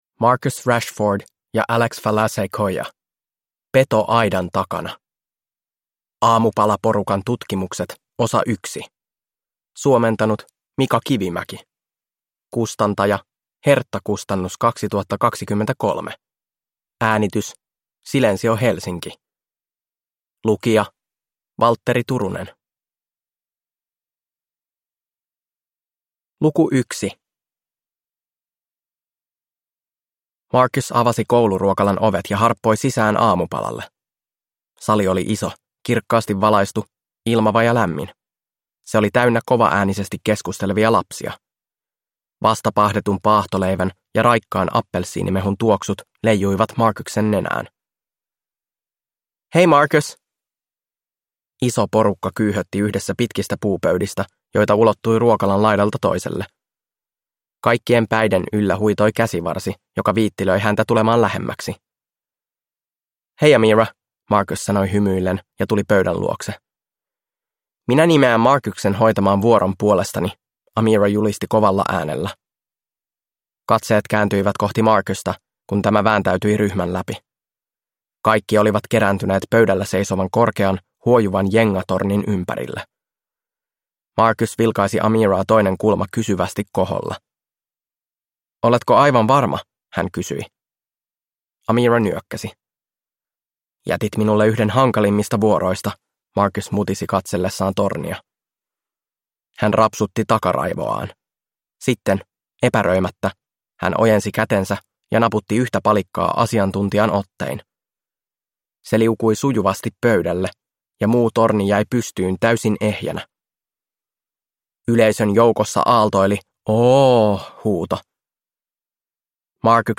Peto aidan takana – Ljudbok – Laddas ner